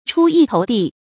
出一头地 chū yī tóu dì
出一头地发音
成语注音 ㄔㄨ ㄧ ㄊㄡˊ ㄉㄧˋ